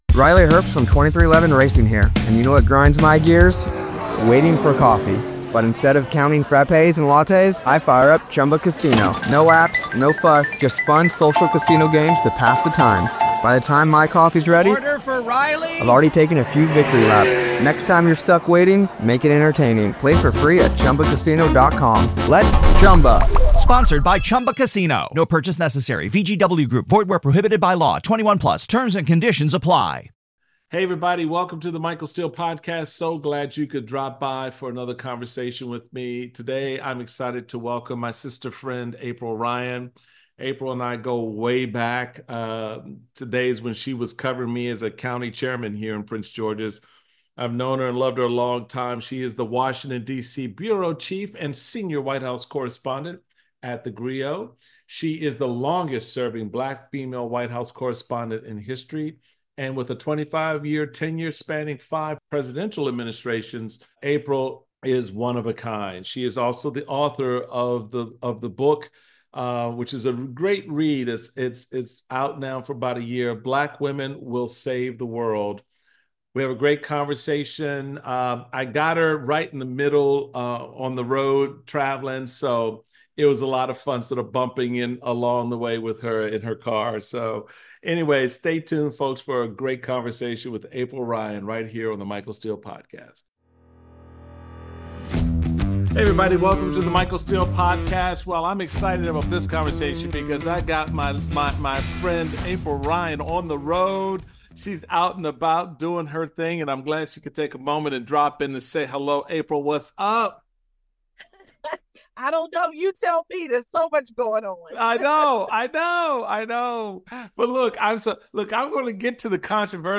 Michael Steele is joined by the longest-serving Black female White House correspondent in history, April Ryan.